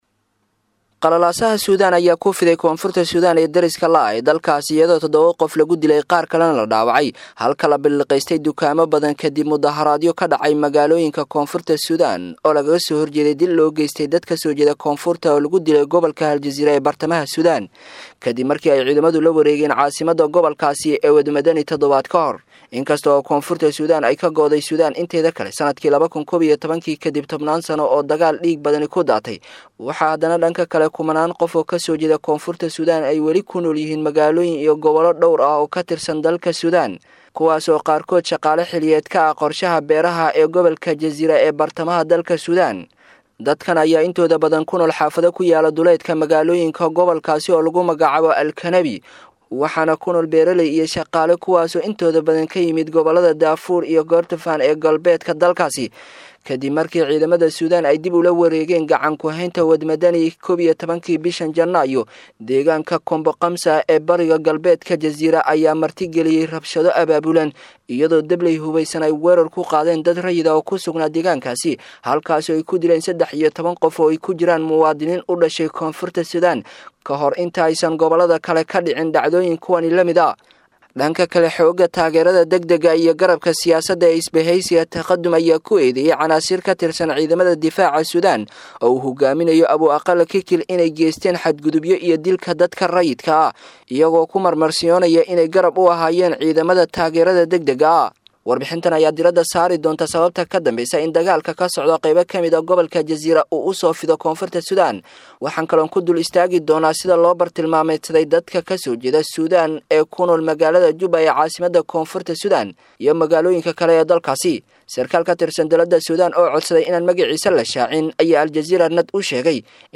Warbixin_Maxay_Yihiin_Rabshadaha_iyo_Qalalaasaha_ka_Taagan_Koonfurta.mp3